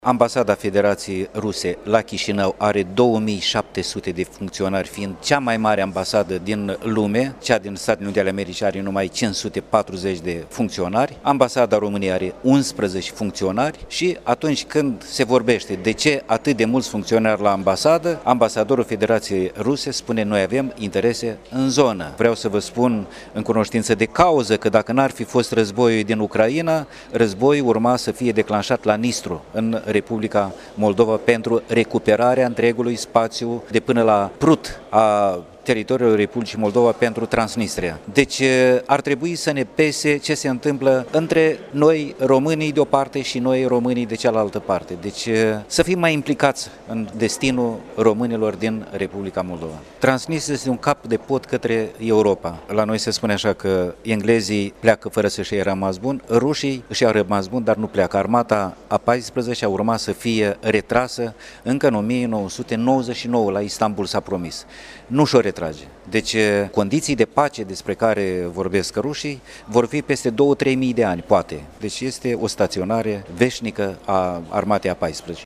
În cadrul Zilelor Academice ieşene, el a ţinut o conferinţă cu tema „Cultura şi unitatea naţională”.